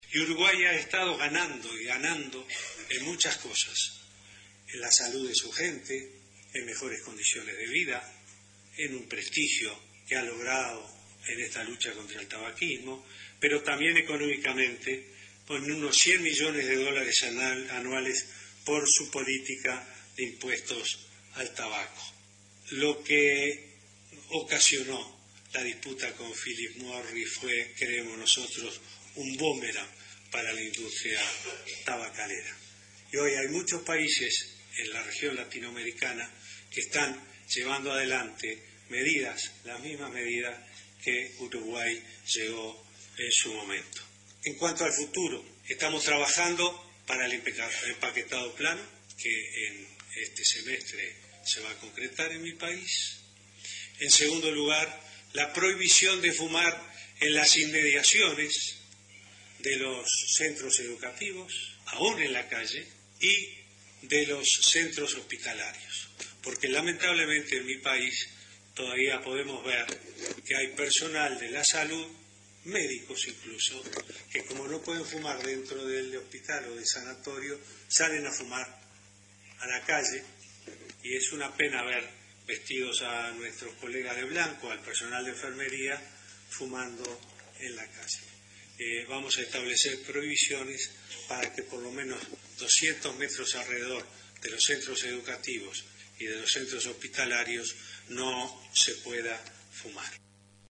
El mandatario realizó el anuncio durante su presentación en el Geneva Graduate Institute denominada “Coordinación multisectorial sobre enfermedades no transmisibles en acción: el caso del control del tabaco”: